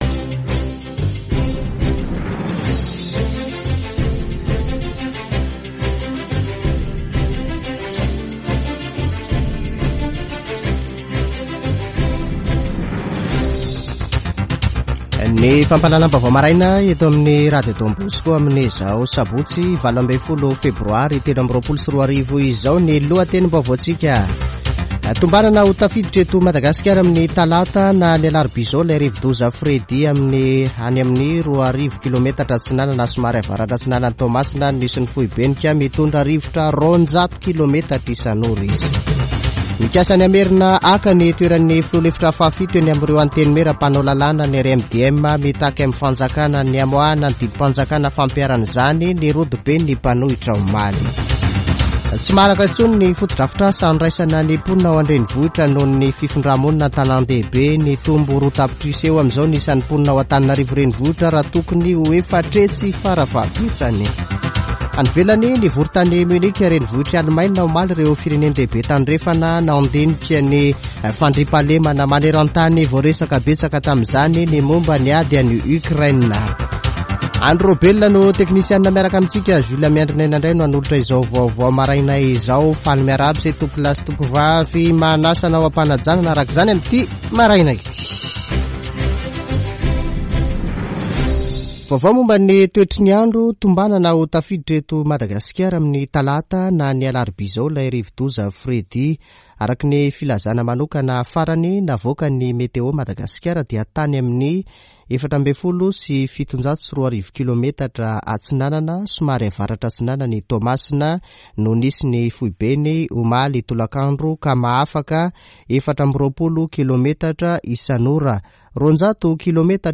[Vaovao maraina] Sabotsy 18 febroary 2023